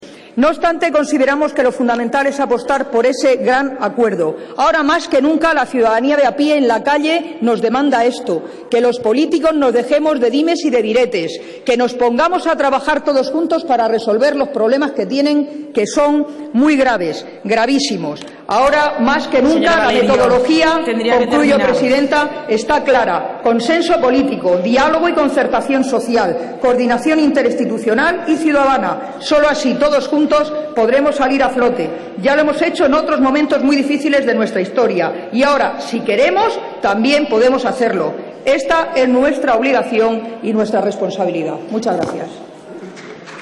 Pleno Congreso de los Diputados 12/03/13